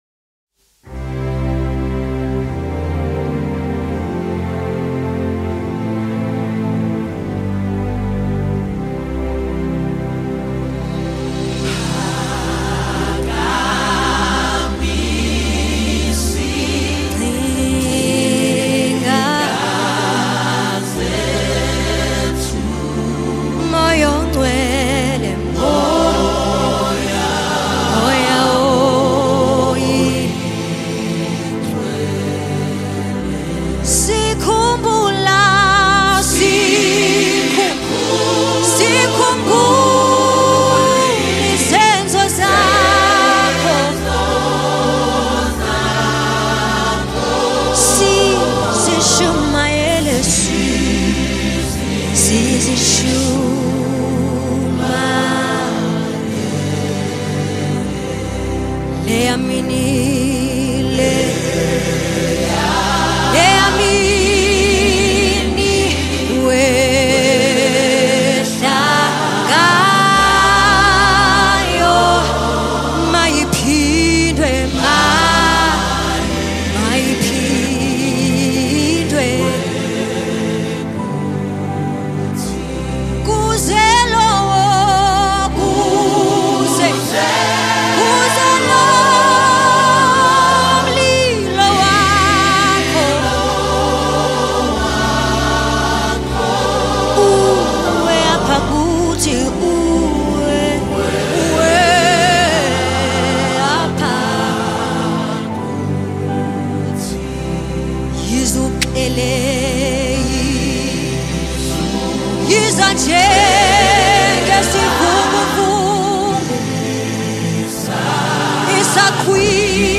Award winning singer-songwriter